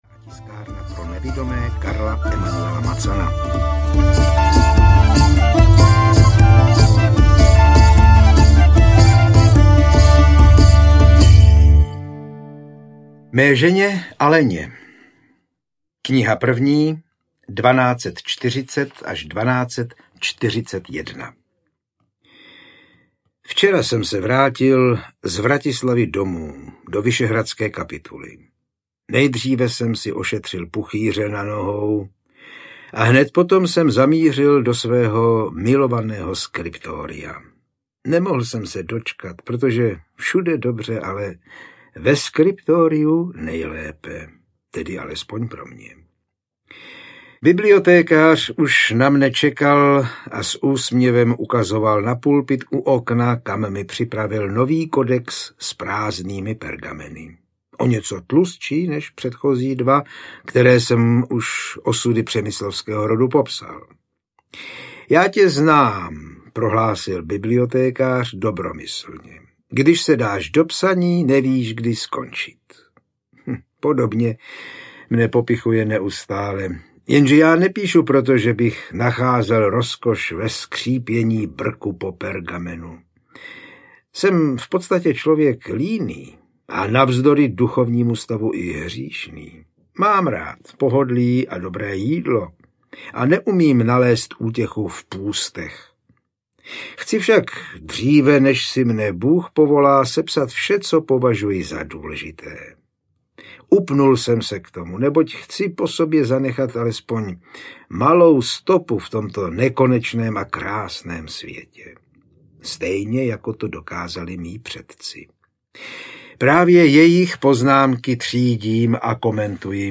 Přemyslovská epopej III - Král rytíř audiokniha
Ukázka z knihy